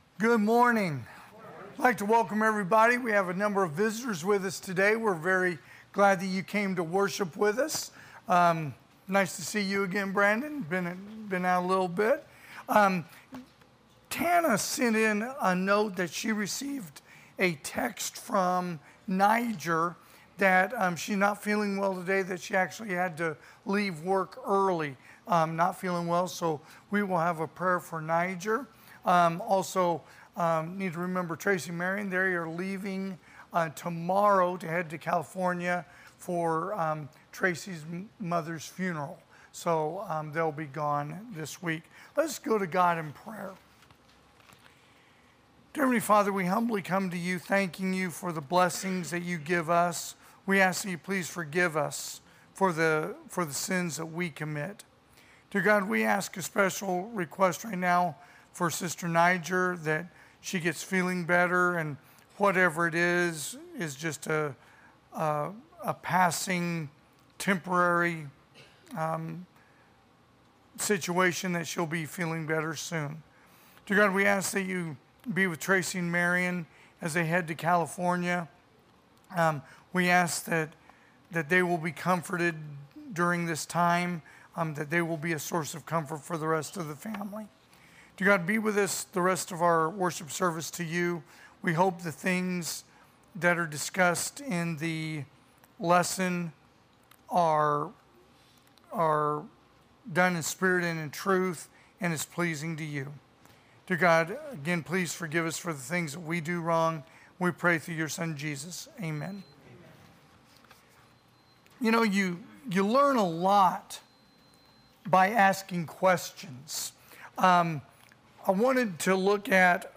2024 (AM Worship) "Questions Asked Of Jesus"